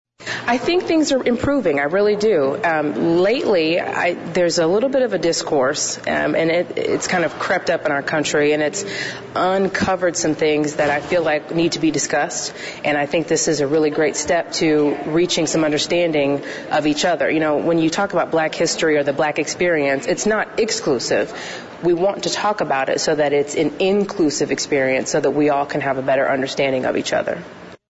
‘’Growing Up Black in America’’ was the title of a panel discussion at Danville Area Community College today (Monday). The DACC Diversity Team hosted the event as part of Black History Month.